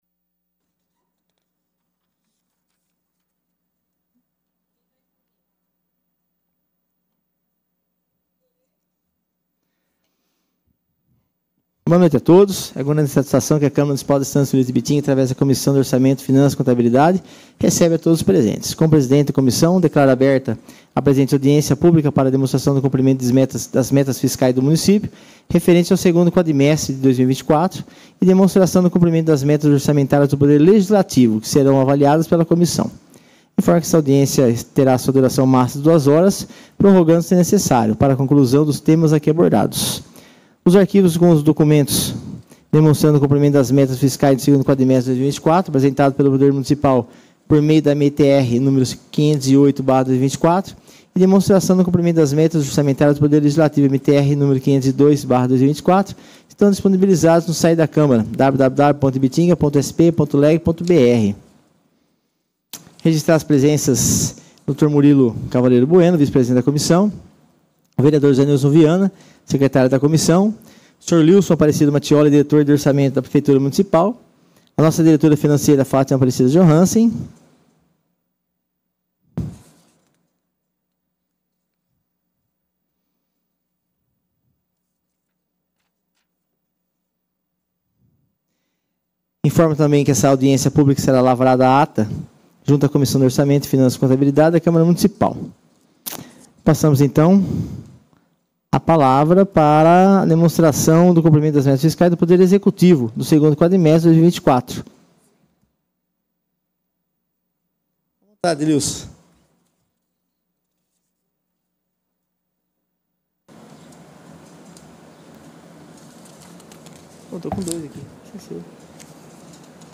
Audiências Públicas
audiencia-metas-fiscais-2-quadr.m4a